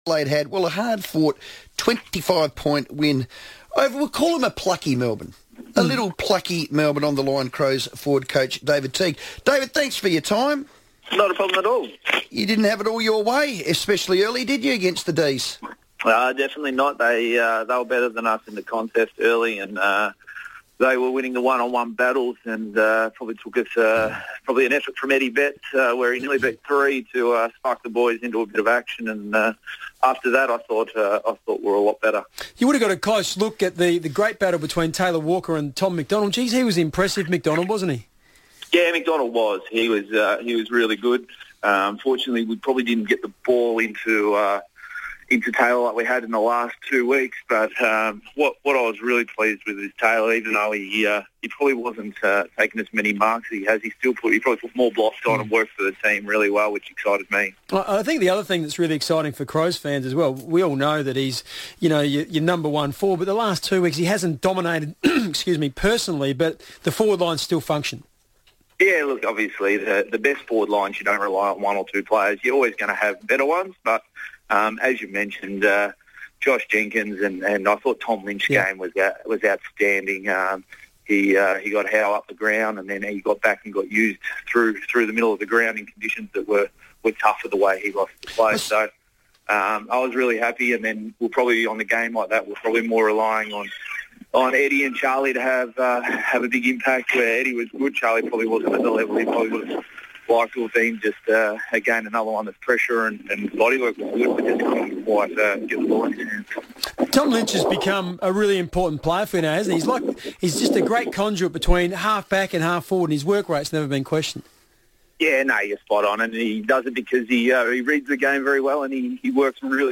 Crows forwards coach David Teague joined the FIVEaa Sports Show ahead of Adelaide's Round Four clash with the Western Bulldogs